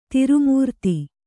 ♪ tiru mūrti